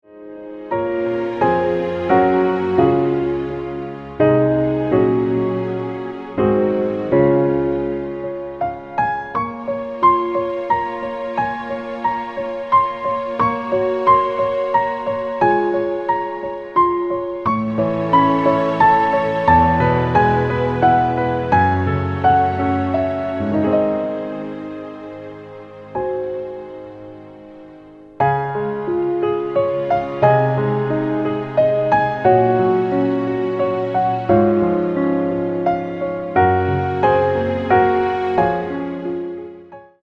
Piano - Strings - Medium